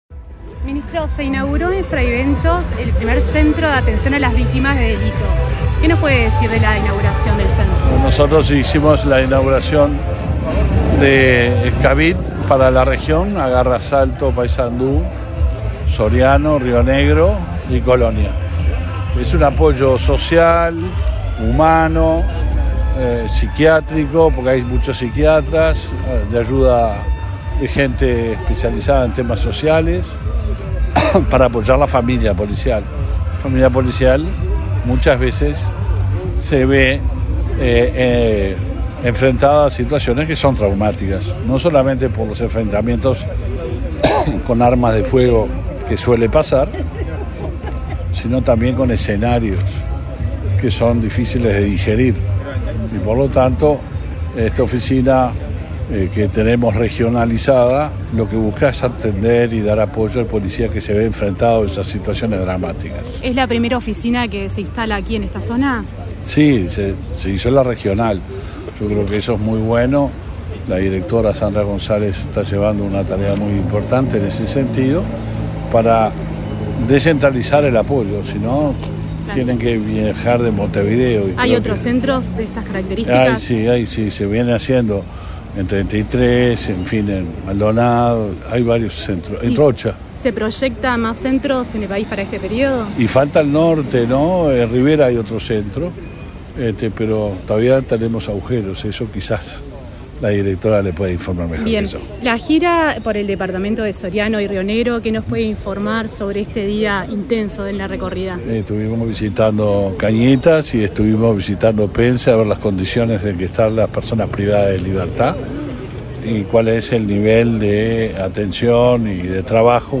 Entrevista al ministro del Interior, Luis Alberto Heber
Entrevista al ministro del Interior, Luis Alberto Heber 07/10/2022 Compartir Facebook X Copiar enlace WhatsApp LinkedIn El ministro del Interior, Luis Alberto Heber, inauguró, este 7 de octubre, un centro de atención a las víctimas de la delincuencia en la ciudad de Fray Bentos, departamento de Río Negro. Tras el evento, el jerarca realizó declaraciones a Comunicación Presidencial.